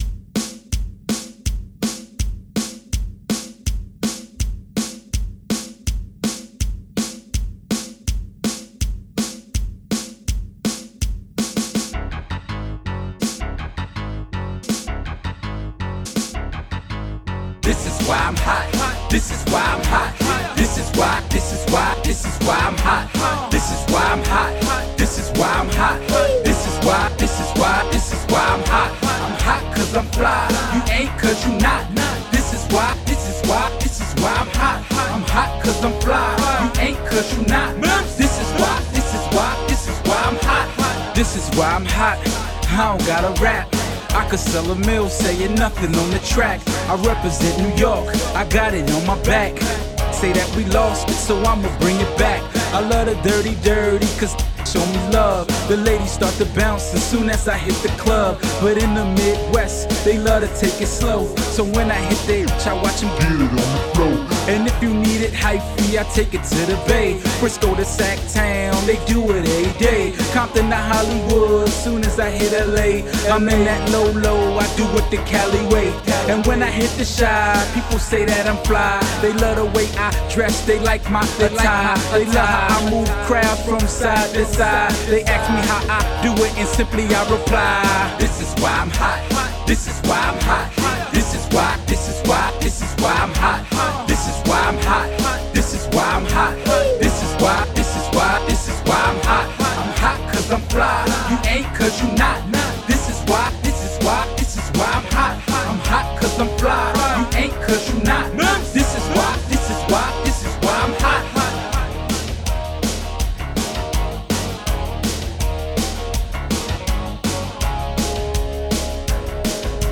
These are unauthorized bootlegs.
Genres: Hip Hop, Rock, Top 40